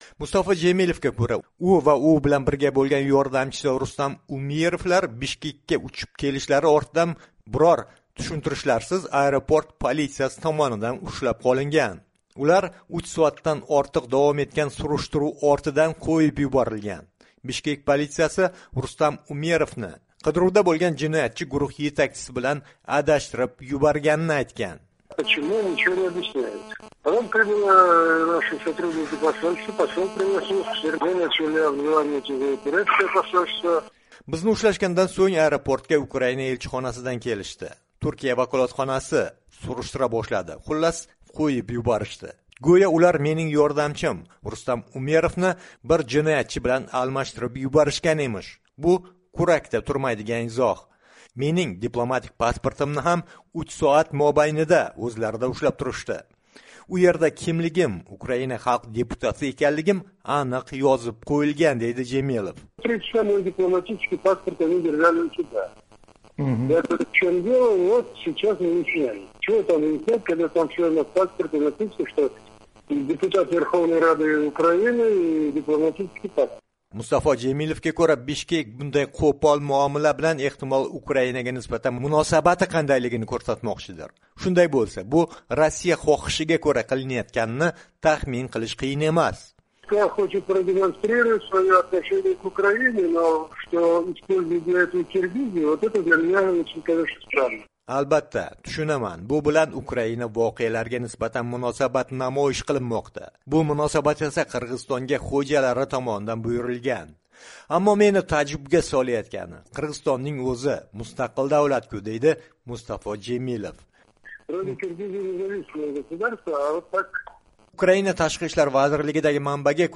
"Amerika Ovozi" bilan suhbatda Jemilev Bishkek Moskva buyutmasiga ko'ra ish tutayotganidan taassuf bildirdi.